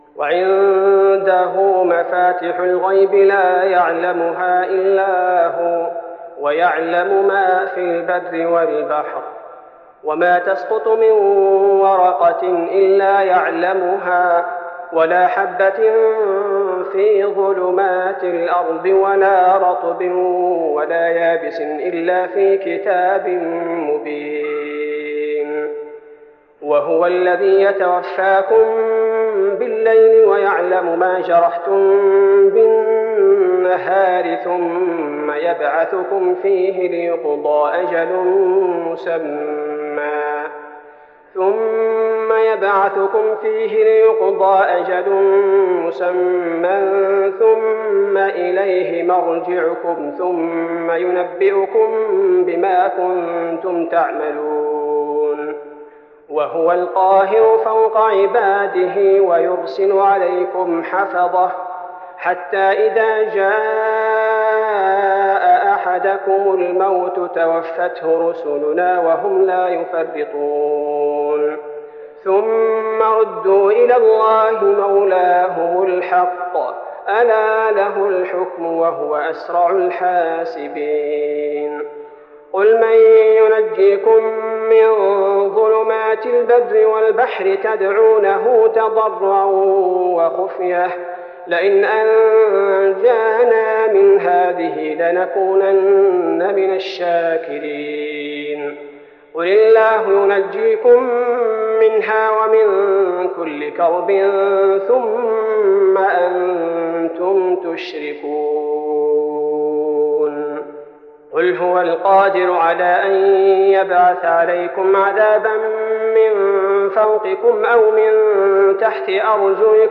تراويح رمضان 1415هـ من سورة الأنعام (59-135) Taraweeh Ramadan 1415H from Surah Al-An’aam > تراويح الحرم النبوي عام 1415 🕌 > التراويح - تلاوات الحرمين